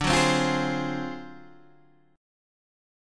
D 7th Sharp 9th Flat 5th